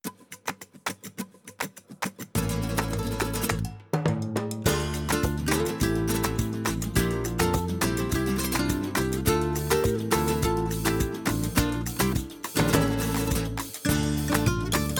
Musique multipistes.